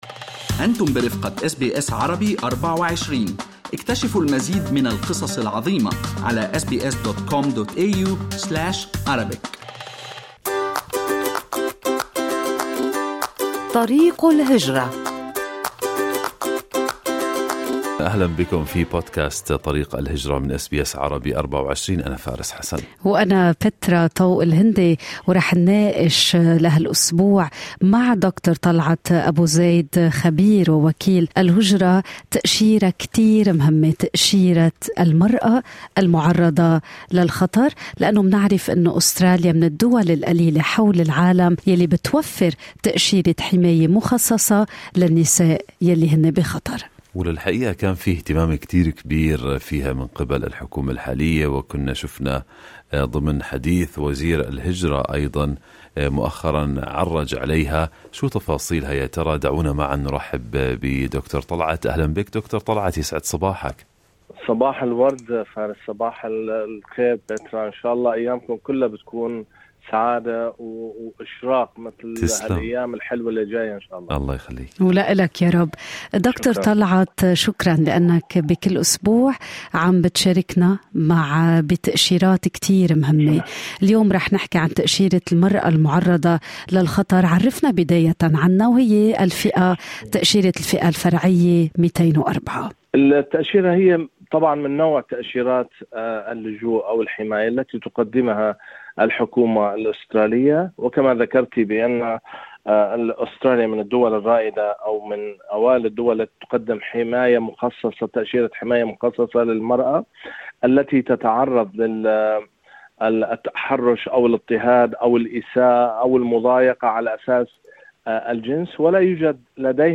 Australia is one of only a handful of countries in the world to offer a special visa for women escaping violence, persecution and harassment. SBS Arabic24 spoke to an immigration lawyer about the Women at Risk Visa Subclass 204.